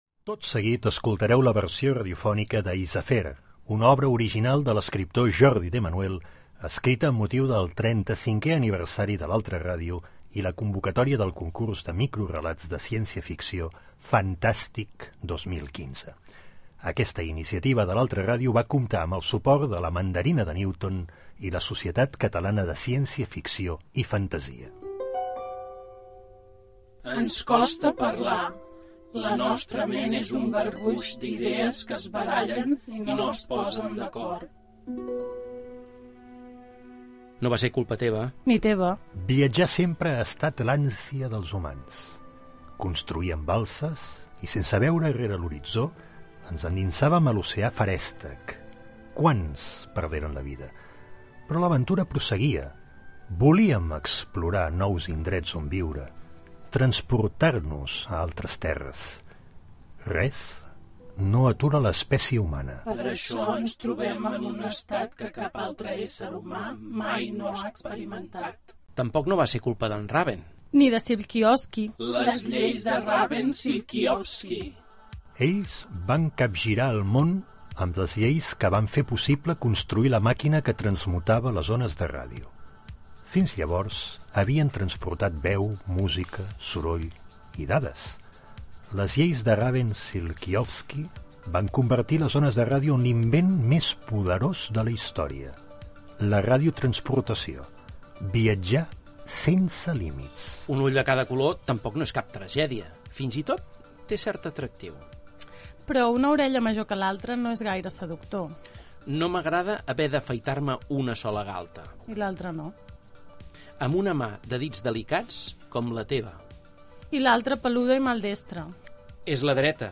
relat radiofònic